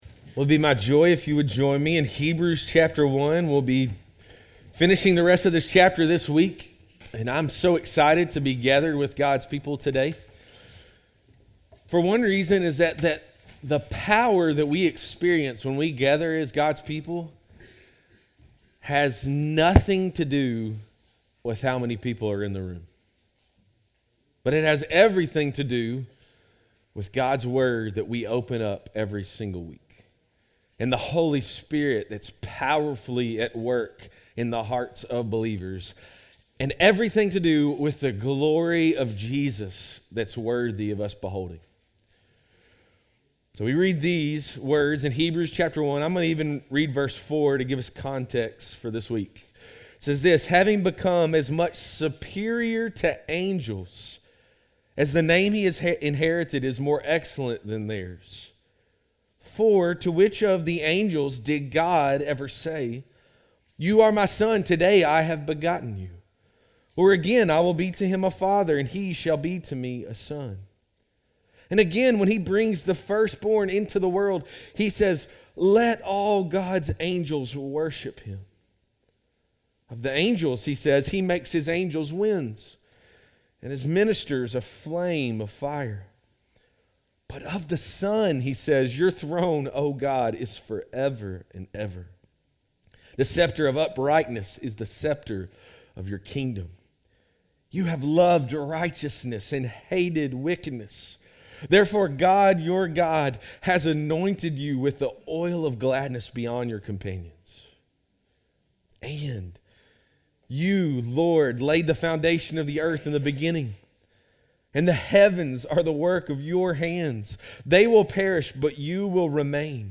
Christ Fellowship Sermons